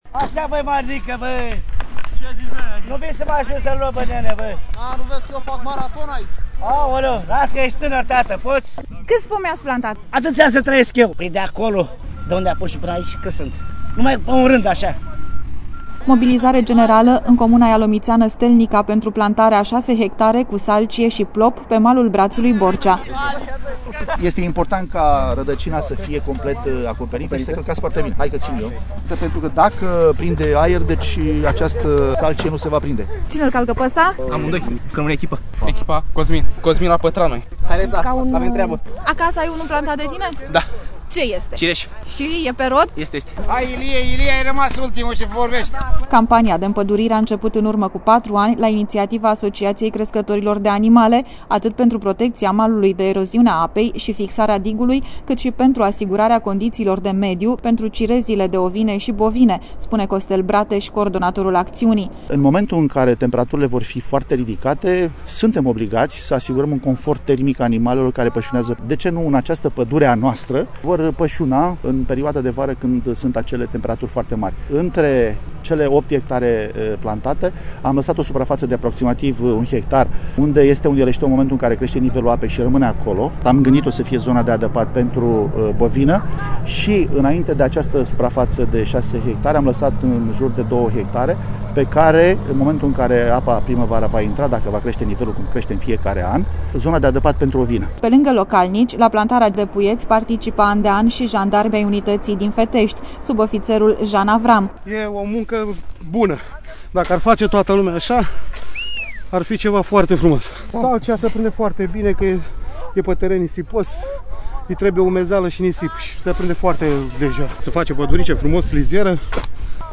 reportaj-plantatie-salcie-si-plop.mp3